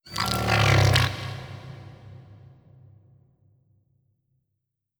khloCritter_Male30-Verb.wav